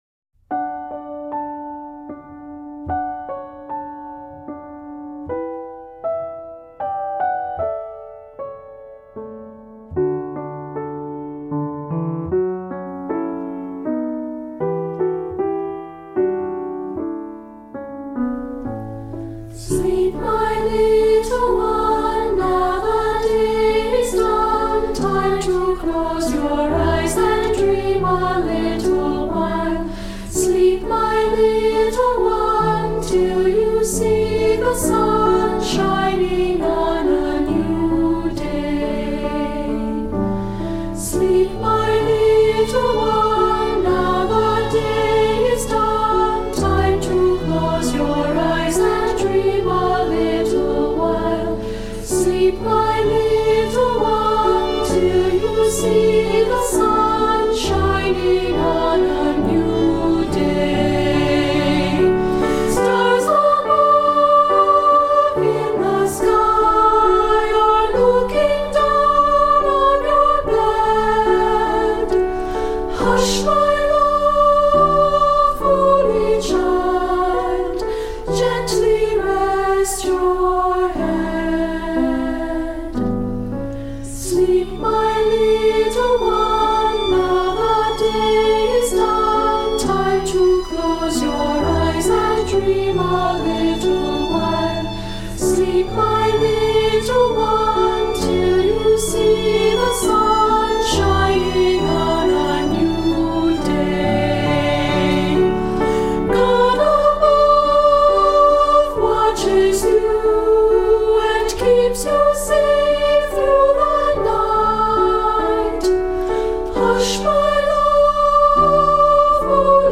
Voicing: Unison or Children’s Choir